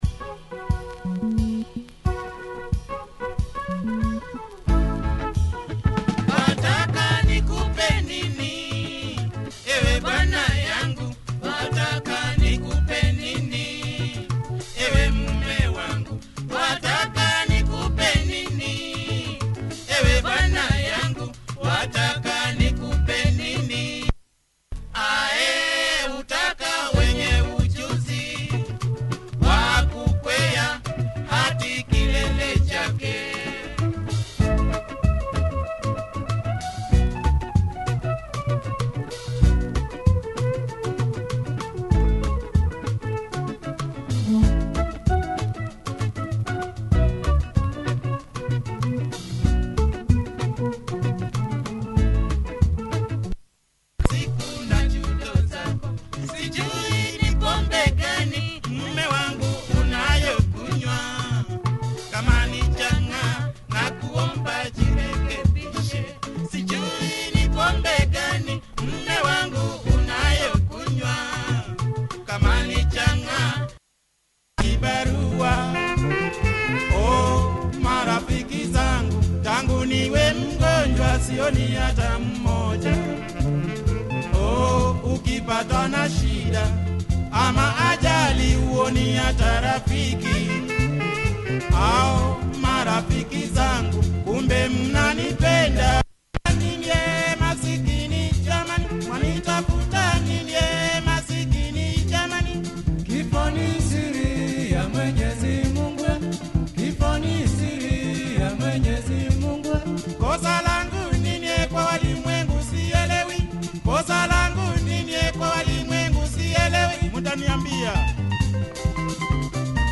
Nice laidback groove on this “Reggae” Lupopo number